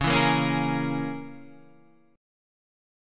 C#m6 chord